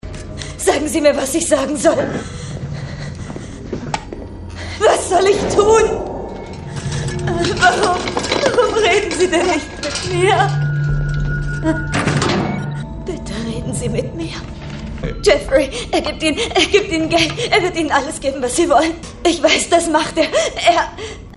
Wandelbare, charakteristische Stimme mit einem Stimmalter von ca 30 bis 50 Jahren.
Sprechprobe: Sonstiges (Muttersprache):